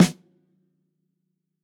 TUNA_SNARE_3.wav